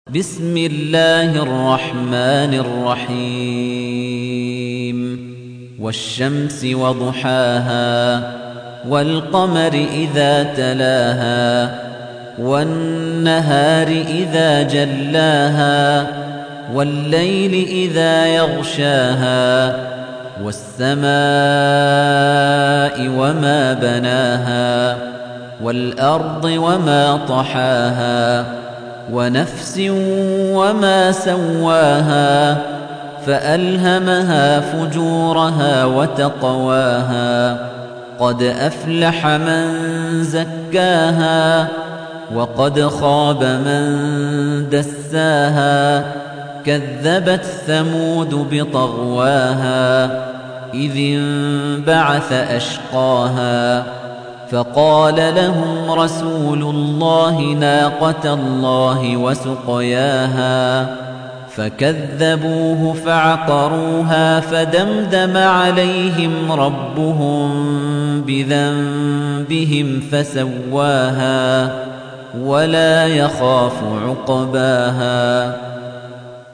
تحميل : 91. سورة الشمس / القارئ خليفة الطنيجي / القرآن الكريم / موقع يا حسين